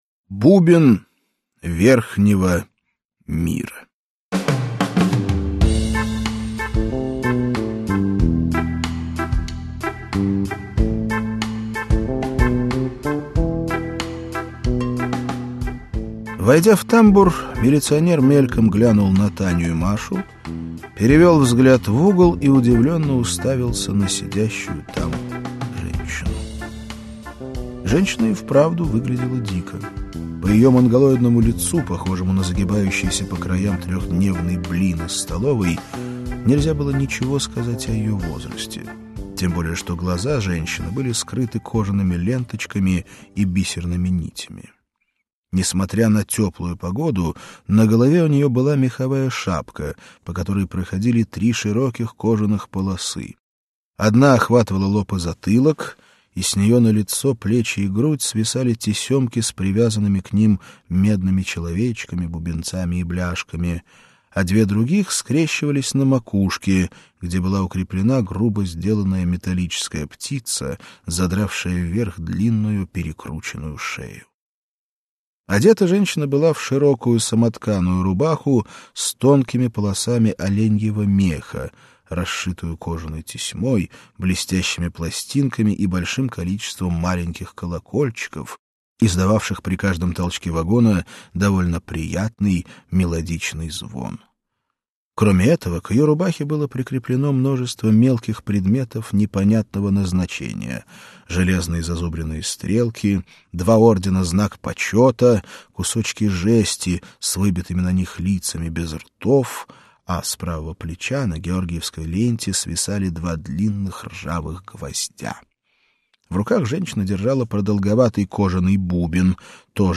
Аудиокнига Лучшие рассказы и повести. Выпуск 1 | Библиотека аудиокниг